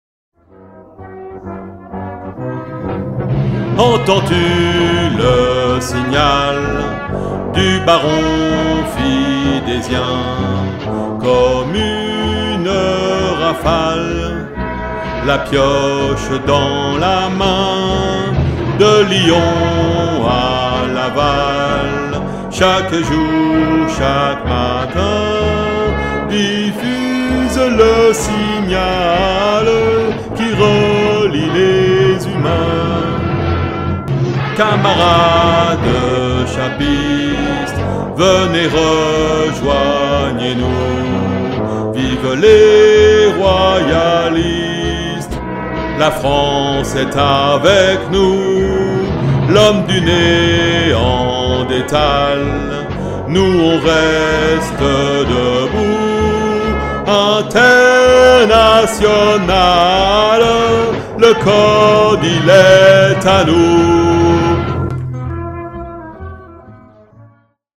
l'enregistrement a été réalisé dans les studios de la MJC de Sainte Foy les Lyon.